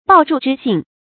抱柱之信 bào zhù zhī xìn 成语解释 后用以表示坚守信约。